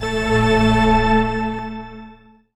orgTTE54033organ-A.wav